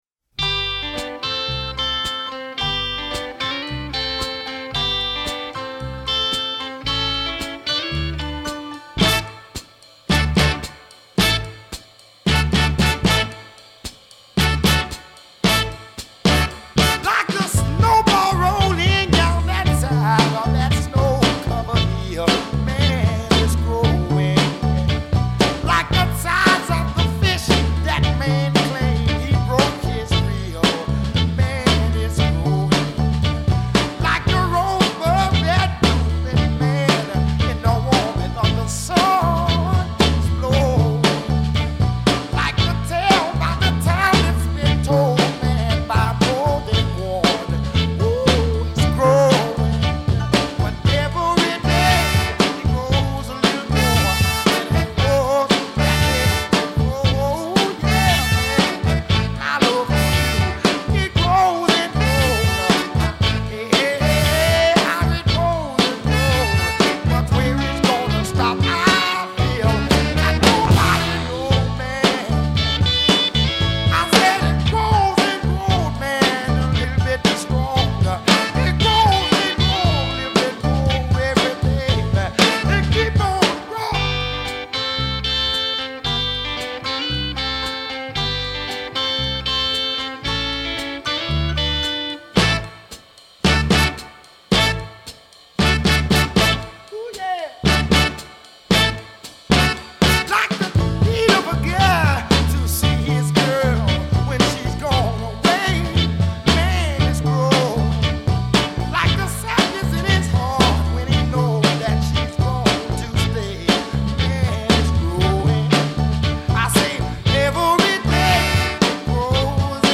And this passionate performance proves this pretty aptly.